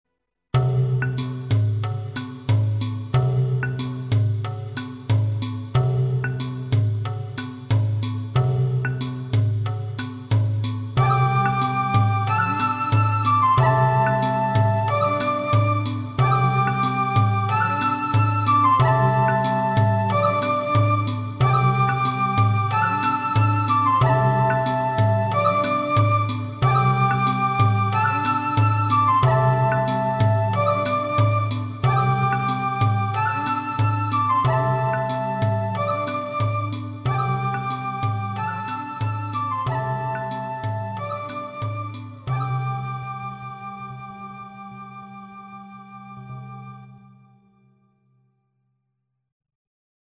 低沉的打击乐仿佛敲响了永乐朝的大钟
梦醒时分，大幕落下，看尽世事，洗尽铅华，带着丝丝感伤、点点无奈，乐声渐渐隐去。